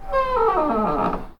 squeaky-door-open-3.ogg